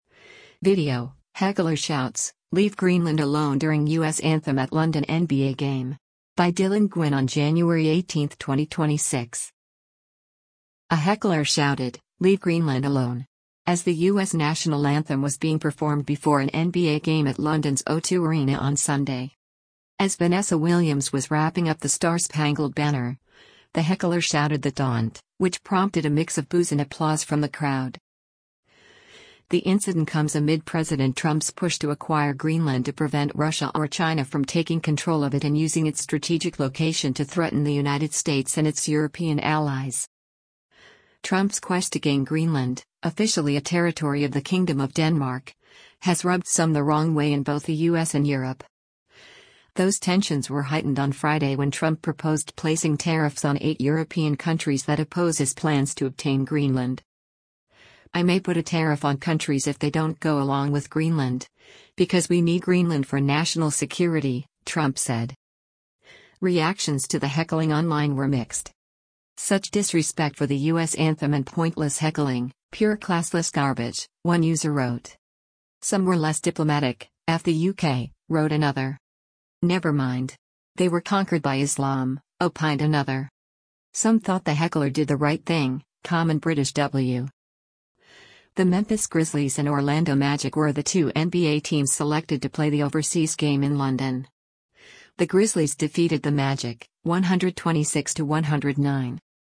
A heckler shouted, “Leave Greenland alone!” as the U.S. national anthem was being performed before an NBA game at London’s O2 Arena on Sunday.
As Vanessa Williams was wrapping up the Star-Spangled Banner, the heckler shouted the taunt, which prompted a mix of boos and applause from the crowd.